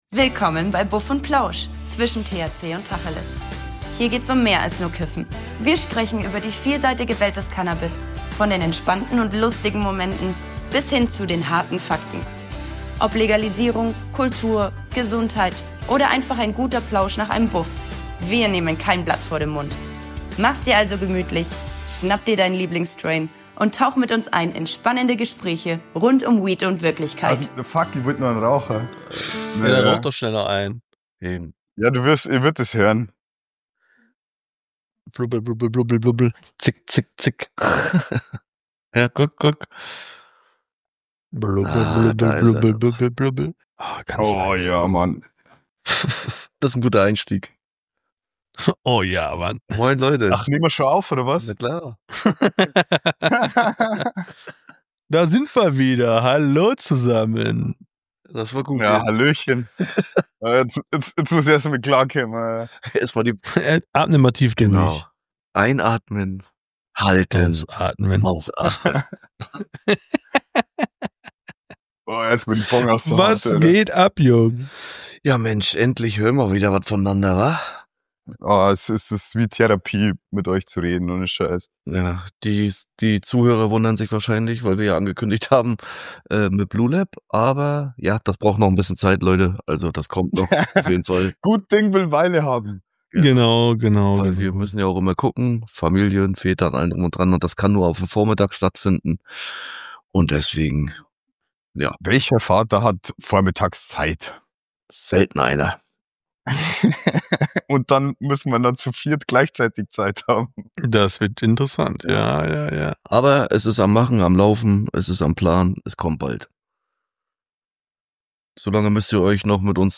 Lockerer Talk, ehrliche Storys und wie immer ein bisschen Buff & Plausch-Vibes.